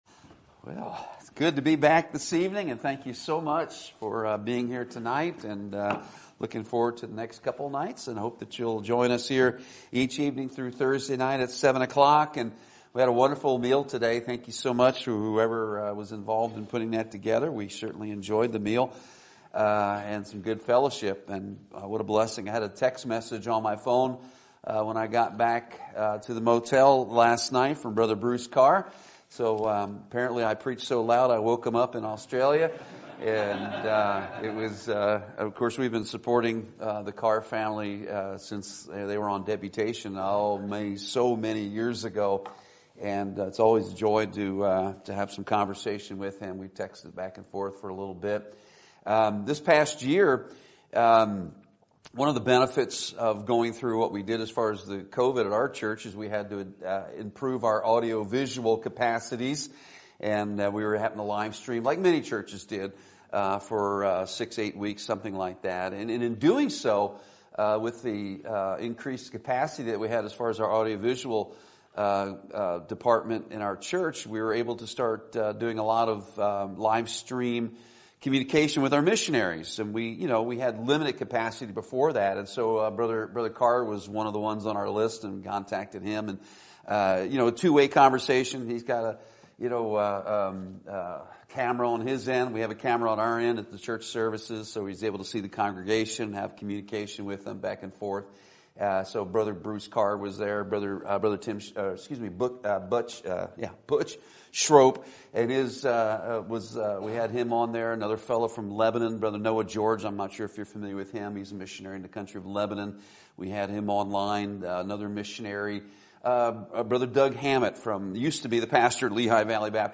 Sermon Topic: Winter Revival Sermon Type: Special Sermon Audio: Sermon download: Download (27.02 MB) Sermon Tags: Nehemiah Revival Prayer Examples